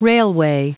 Prononciation du mot railway en anglais (fichier audio)
Prononciation du mot : railway